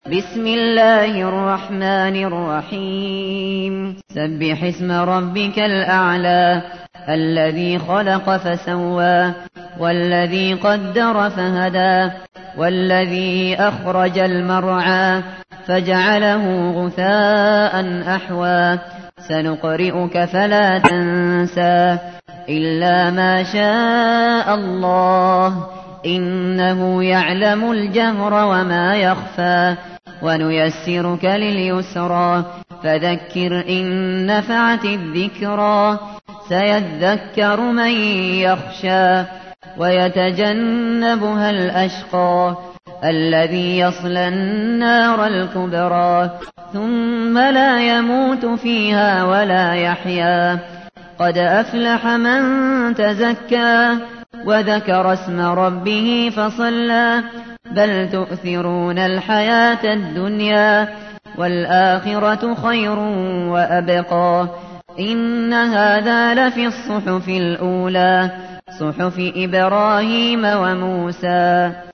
تحميل : 87. سورة الأعلى / القارئ الشاطري / القرآن الكريم / موقع يا حسين